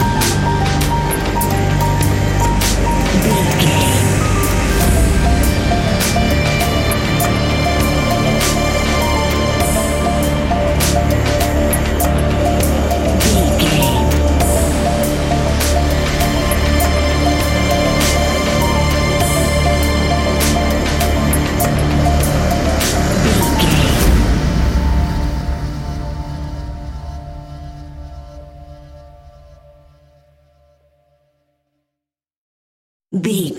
Ionian/Major
D♭
industrial
dark ambient
EBM
synths